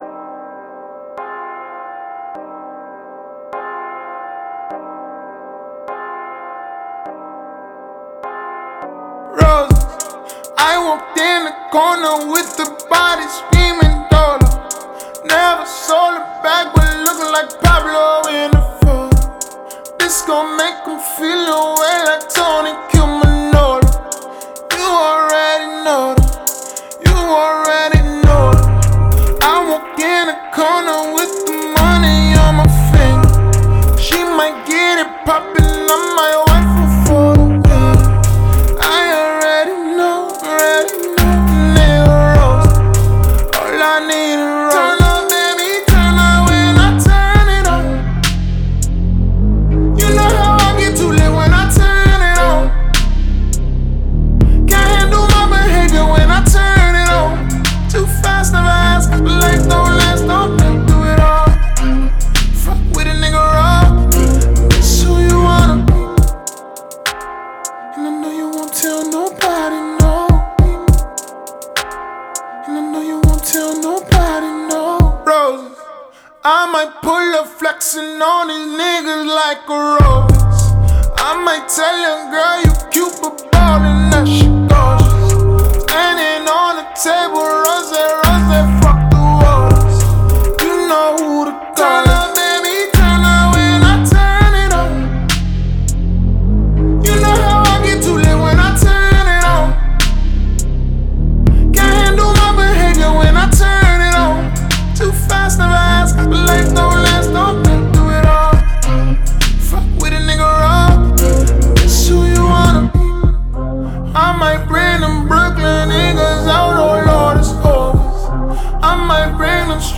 Зарубежный рэп